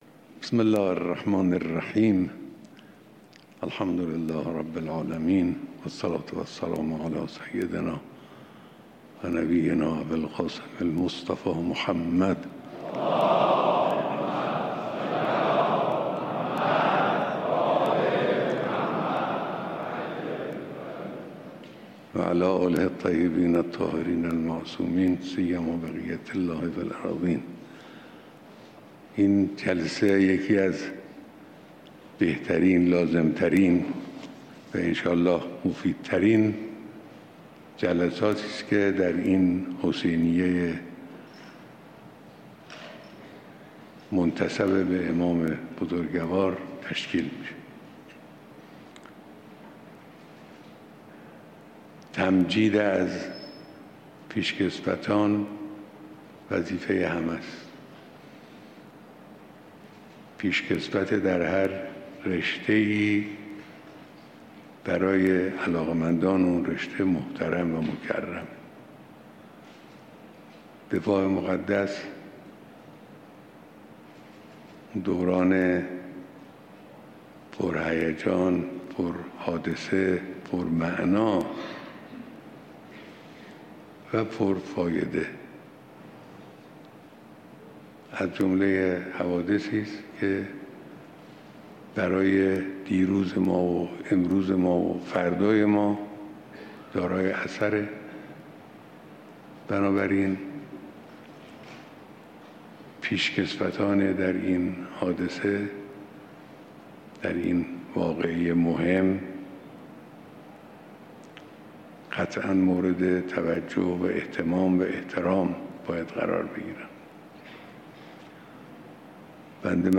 بیانات در دیدار جمعی از پیشکسوتان دفاع مقدس